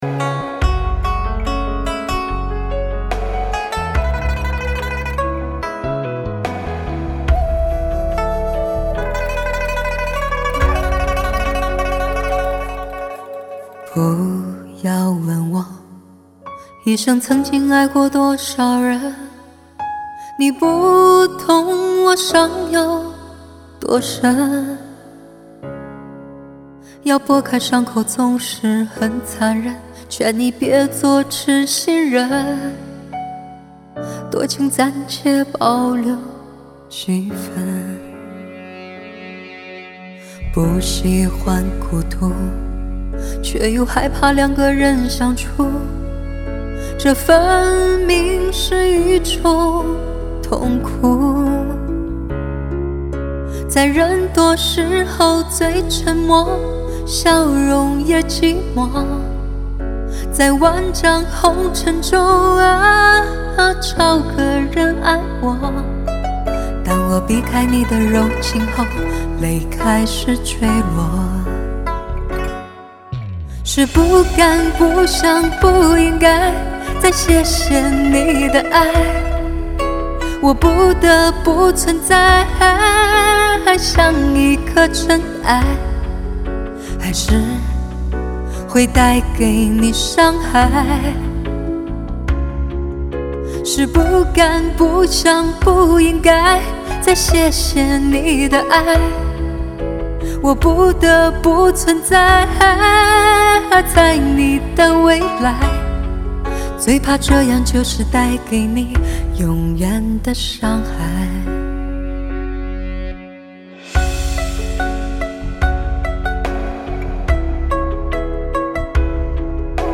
流行
伤感浓情，听觉极品。
嗓音独特饱含张力，悠扬而深邃，炽热而浓挚，纯净而飘逸